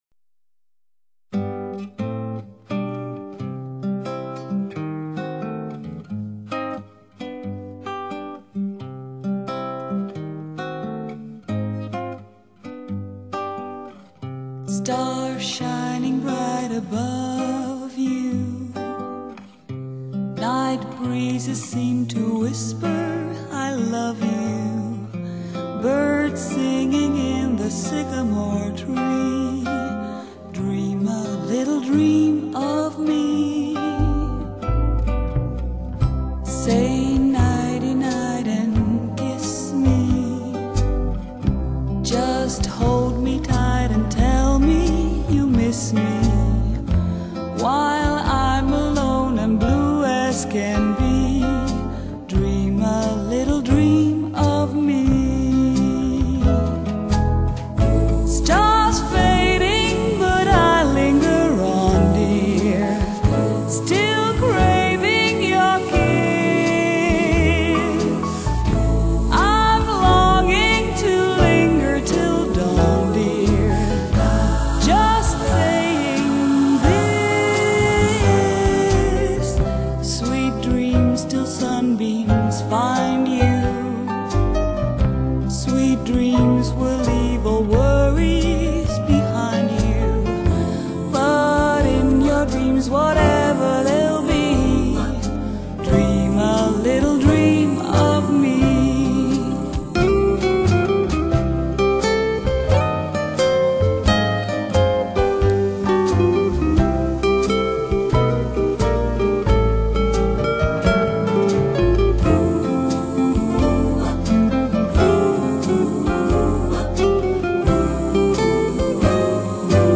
此張爵士情歌，專爲注重品味人生，
享受生活的時尚一族打造的沙發音樂。
日本JVC特別低音處理，試聽時注意調節BASS